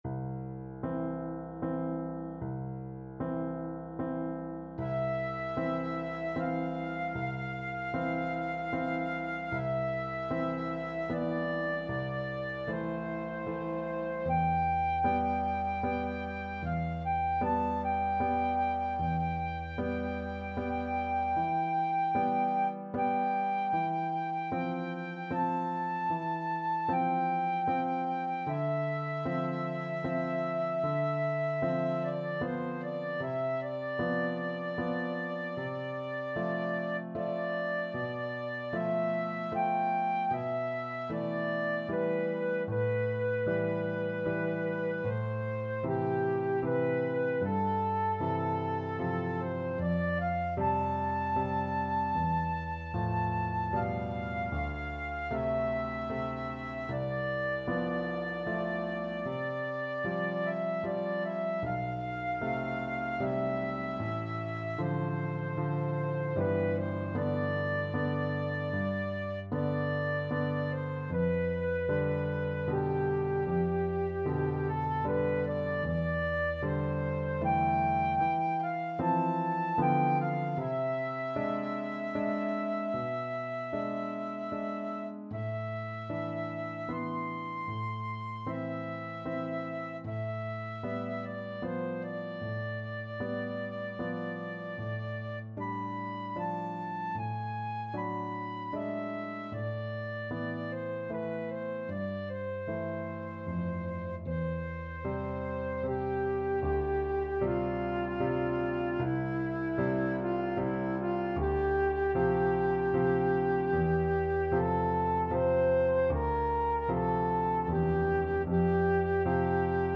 Adagio assai =76
3/4 (View more 3/4 Music)
Classical (View more Classical Flute Music)